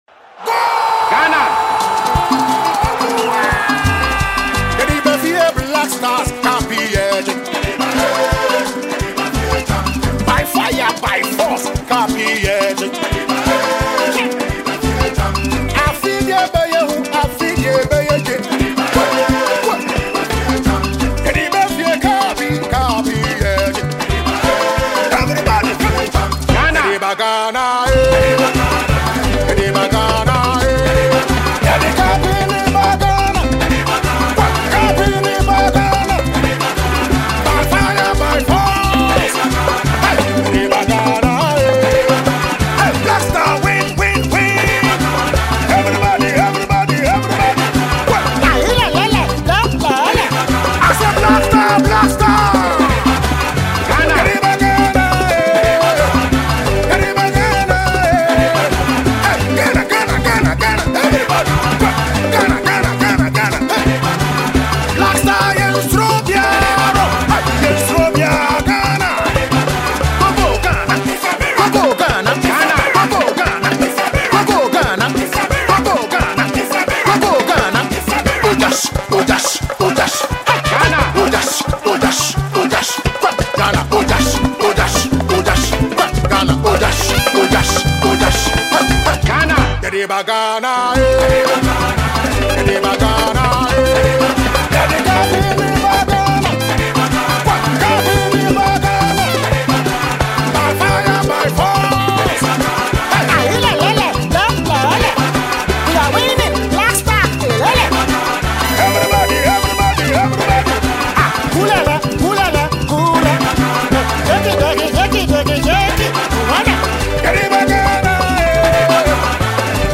Ghana Music
Ghanaian gospel musician